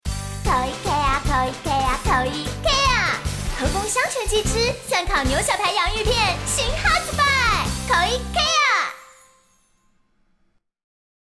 國語配音 女性配音員